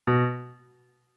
MIDI-Synthesizer/Project/Piano/27.ogg at 51c16a17ac42a0203ee77c8c68e83996ce3f6132